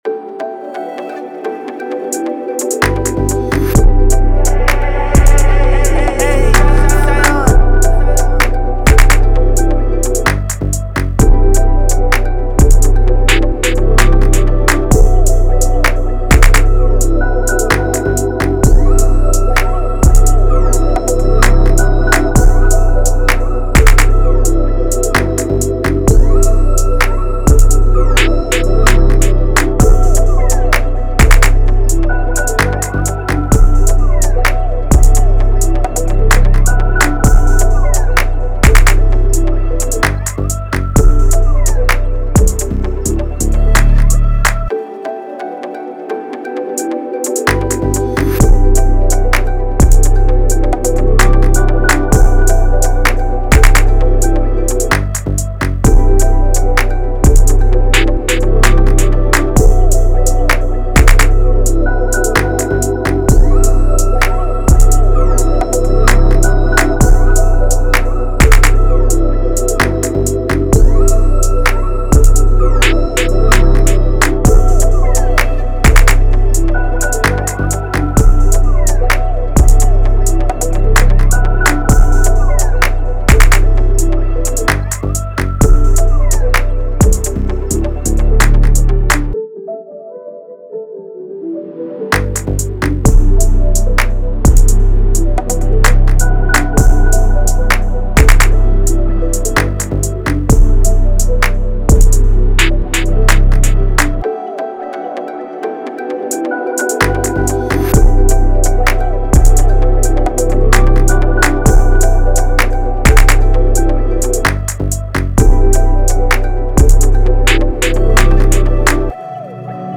129 B Minor